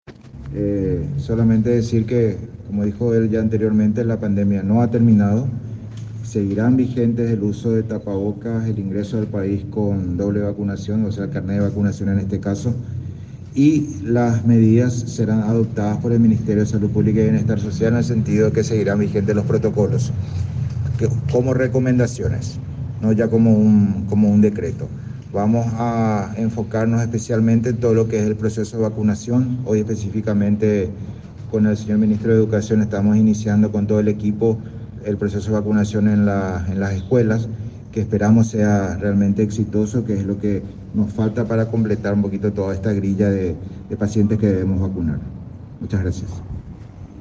12-JULIO-BORBA-CONFERENCIA.mp3